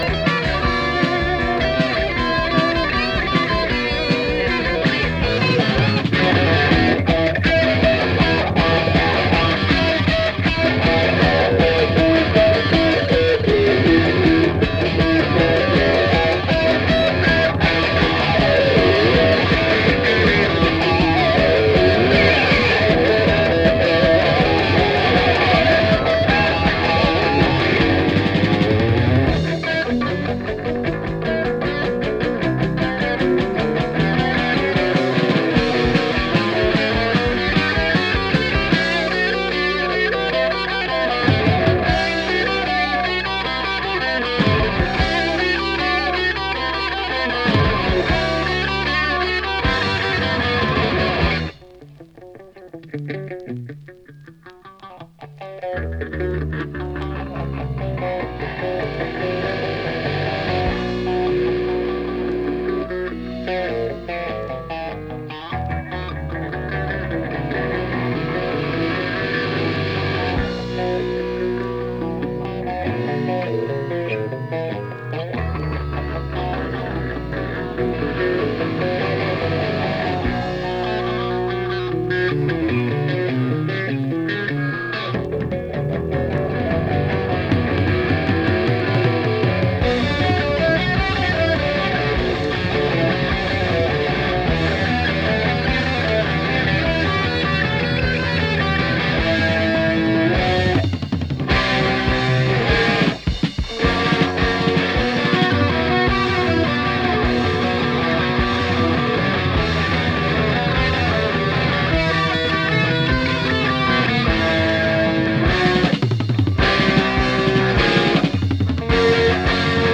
緩急をつけた組曲形式がドラマチックでスリリング、インスト主体でジャズロック・テイストも感じさせる演奏に、
お互いの手の内を知り尽くしたような二人のギターの絡みが絶妙なプログレ・ハードです！